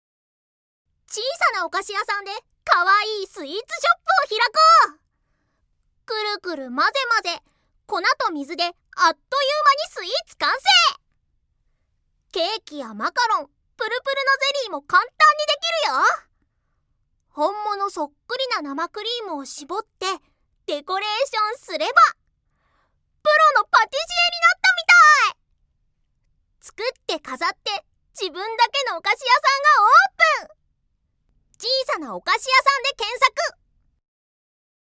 ◆キャラもの◆